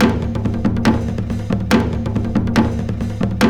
KOREA PERC 2.wav